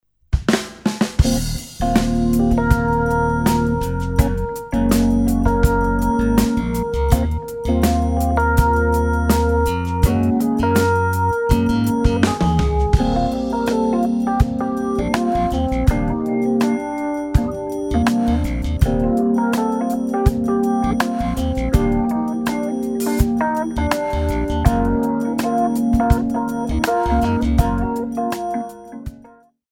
4 bar intro
moderato
Jazz-Funk / Jazz / Lyrical Jazz